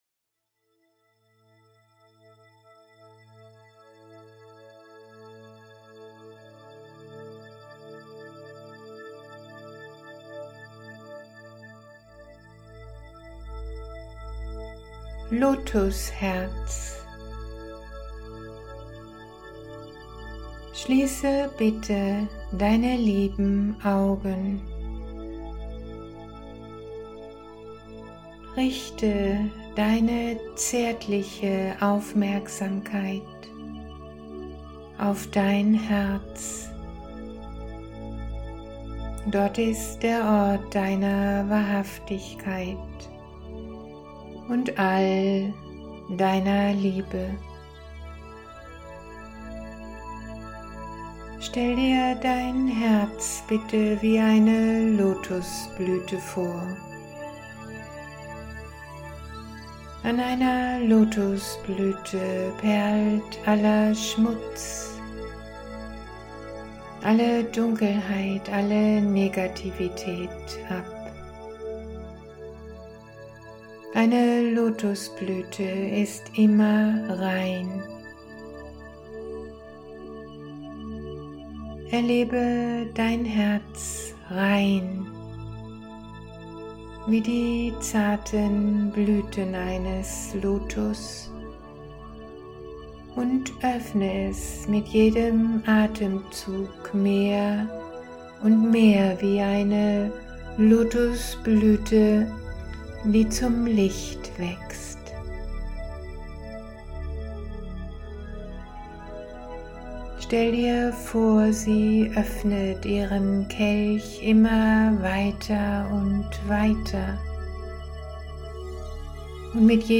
Hörbuch